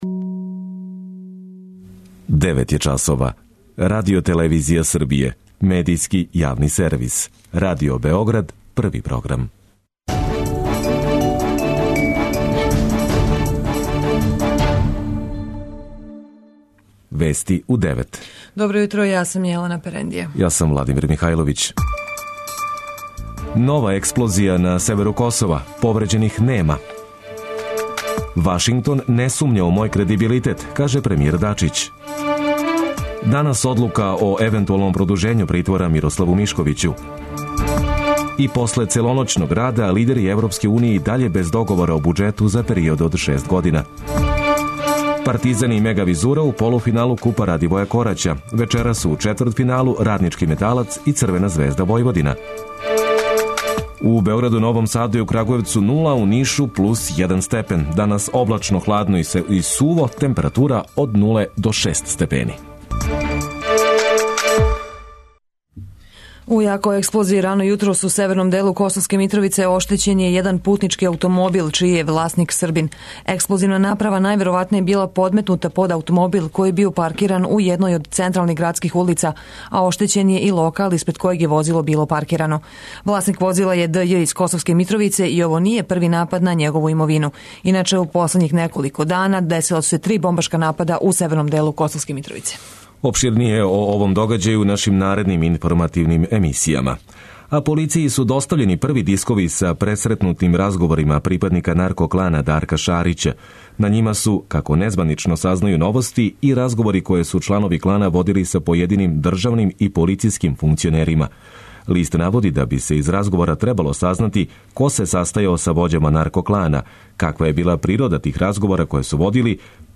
преузми : 9.53 MB Вести у 9 Autor: разни аутори Преглед најважнијиx информација из земље из света.